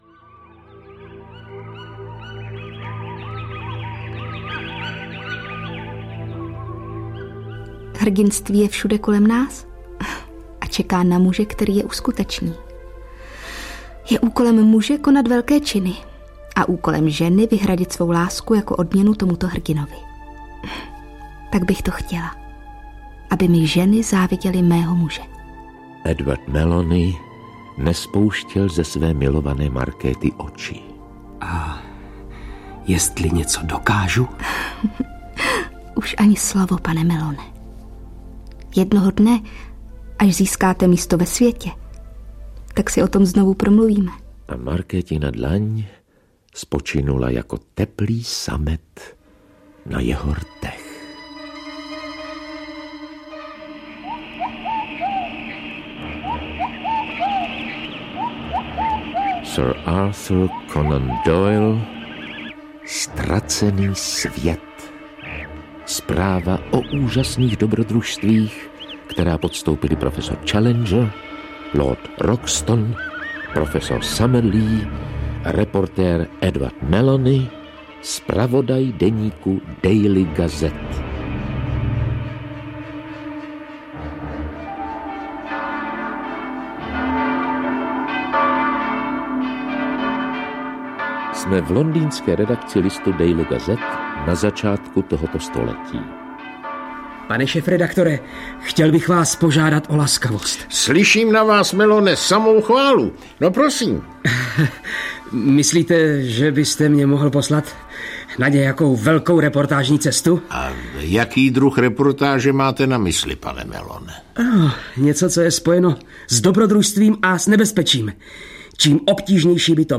Ztracený svět audiokniha
Ukázka z knihy
• InterpretRudolf Pellar